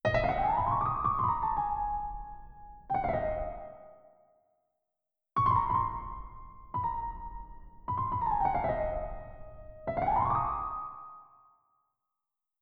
Piano-Inciso-Flauto.wav